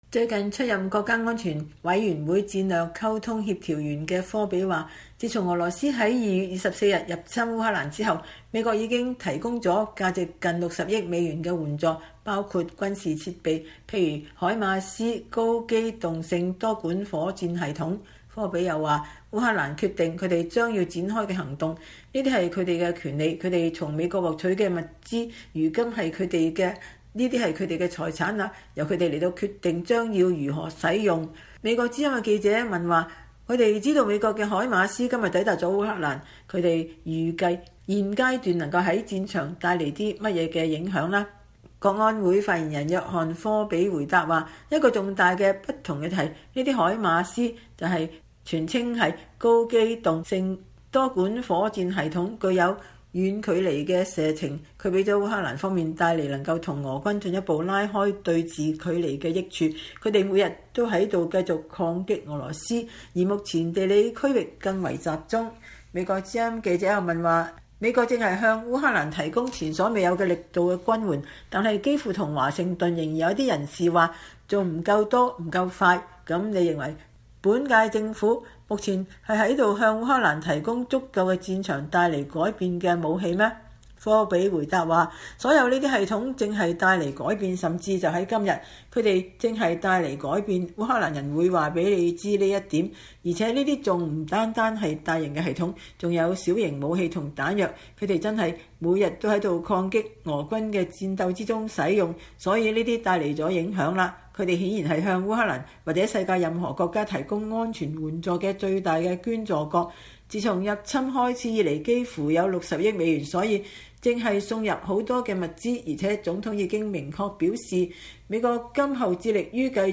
VOA專訪美國安會發言人:如何使用美國軍援由烏克蘭決定,澤連斯基將界定何為勝利